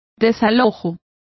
Complete with pronunciation of the translation of vacations.